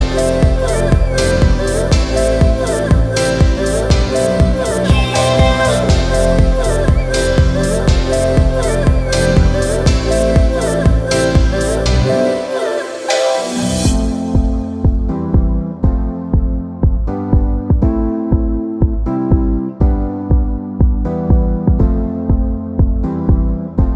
Duet Version Pop (2010s) 4:45 Buy £1.50